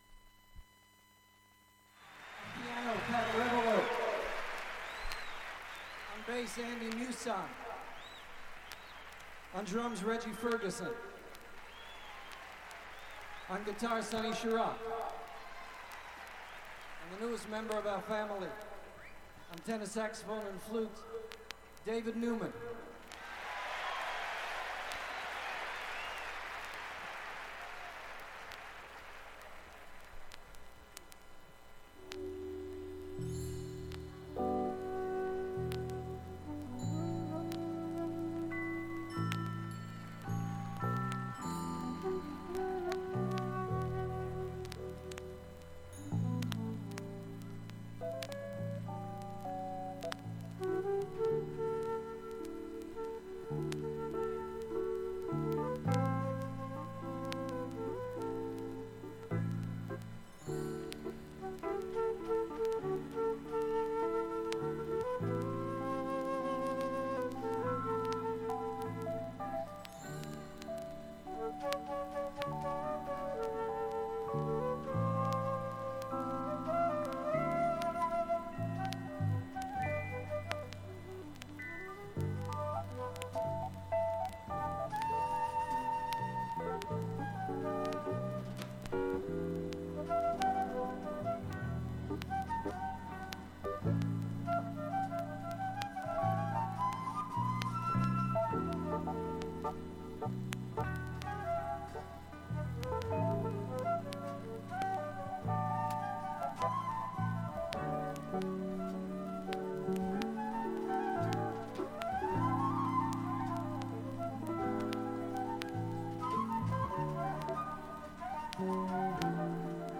出ますが、かなり小さめです。
現物の試聴（上記録音時間5分）できます。音質目安にどうぞ
エキサイティングなライブアルバム